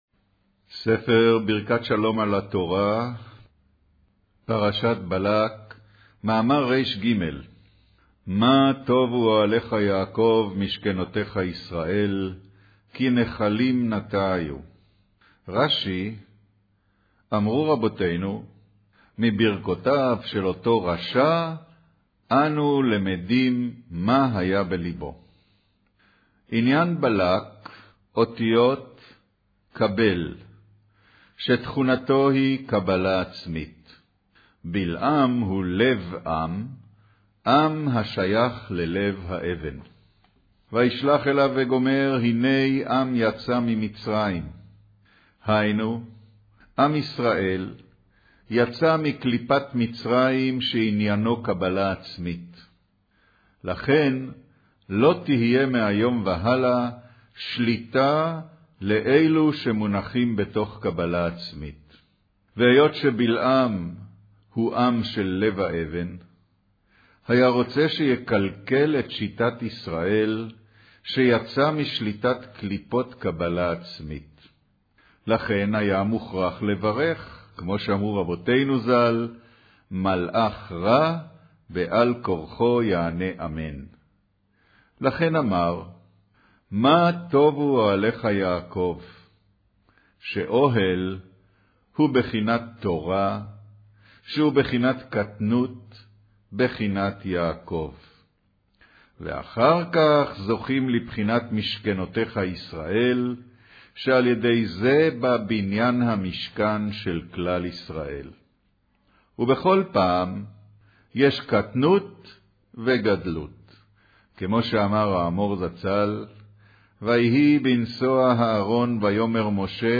אודיו - קריינות פרשת בלק, מאמר מה טבו אהליך יעקב משכנתיך ישראל